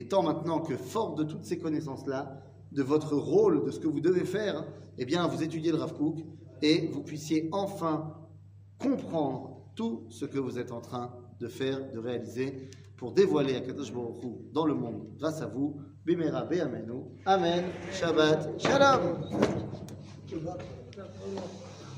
Parachat Ki Tavo, Je vous laisse la place 00:32:53 Parachat Ki Tavo, Je vous laisse la place שיעור מ 29 אוגוסט 2023 32MIN הורדה בקובץ אודיו MP3 (344.53 Ko) הורדה בקובץ וידאו MP4 (2.55 Mo) TAGS : שיעורים קצרים